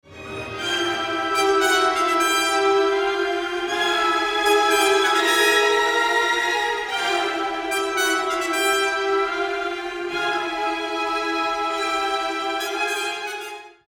I tak u Stokowskiego trąbki są:
Stoki-trabka.mp3